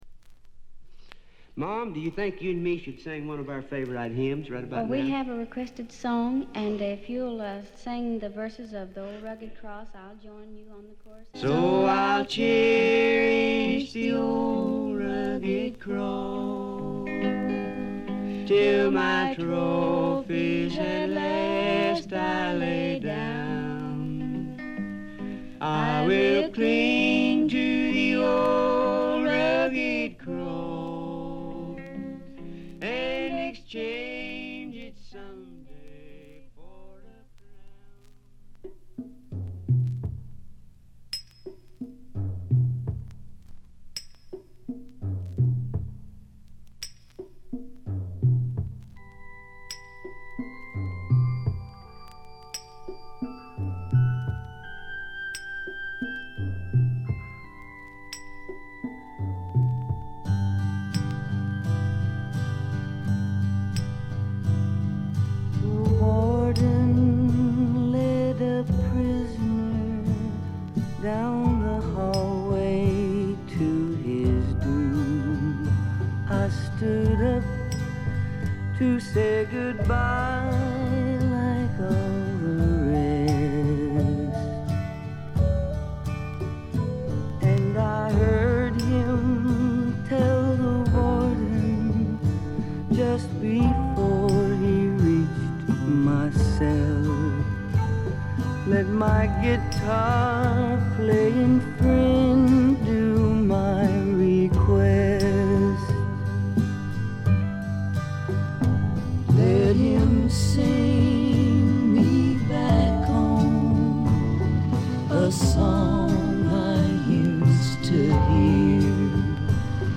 ところどころでチリプチ。
試聴曲は現品からの取り込み音源です。